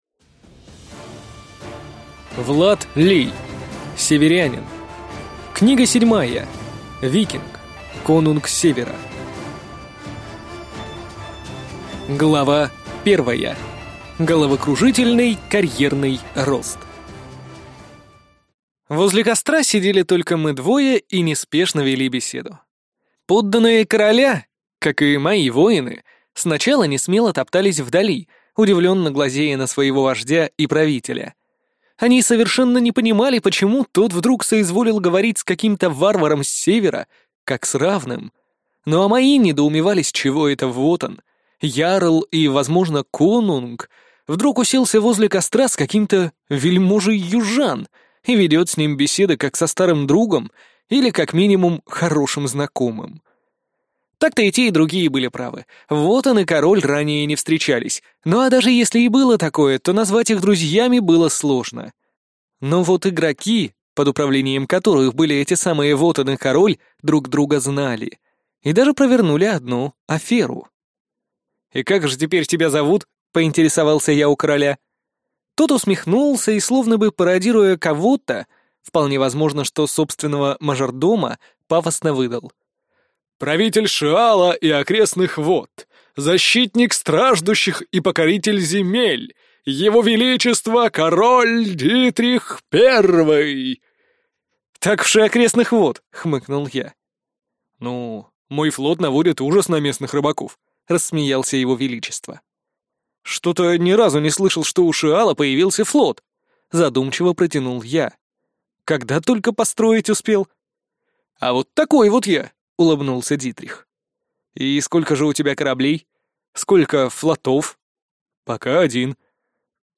Аудиокнига Северянин. Книга 7. Викинг. Конунг Севера | Библиотека аудиокниг